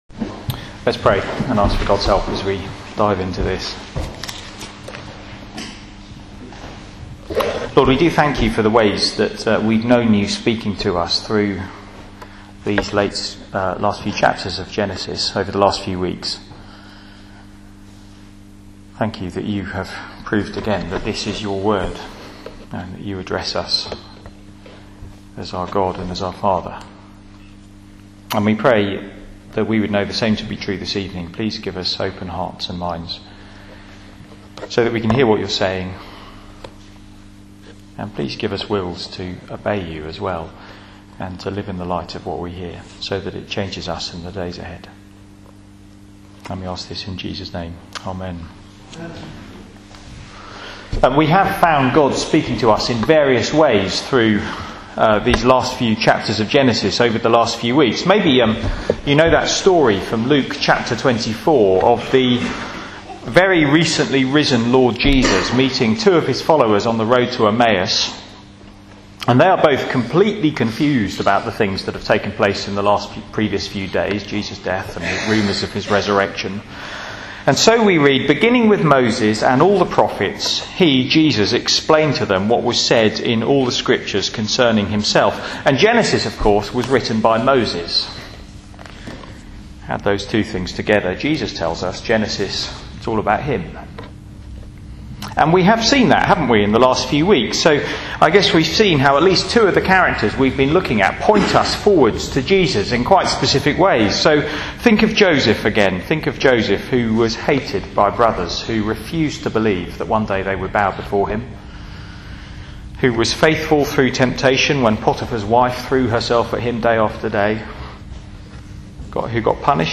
Media for Sunday Evening on Sun 03rd Jun 2012 19:00
Theme: All's Well That Ends Well Sermon